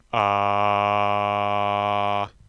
Make vocal harmonies by adding sounds where the pitch has been altered by specified semitone intervals.
Example of harmonized sounds:
Original . Major chord . Minor chord . Major 7th chord